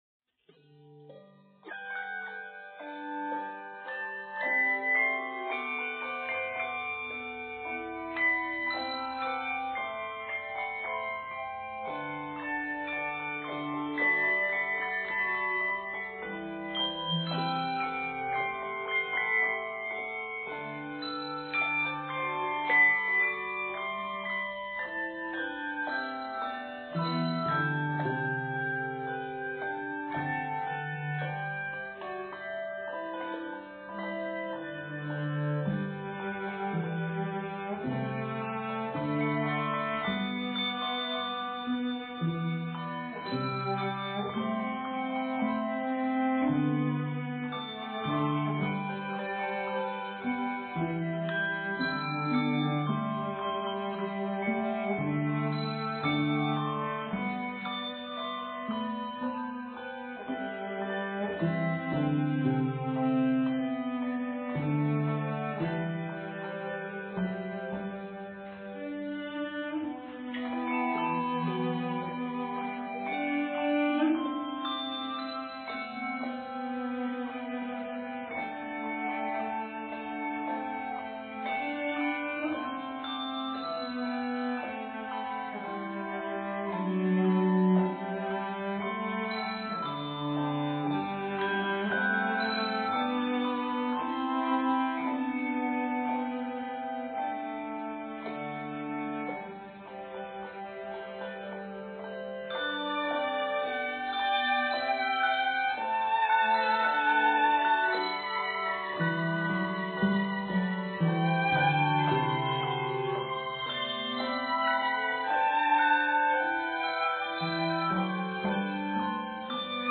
hymn tune
solo violin and solo cello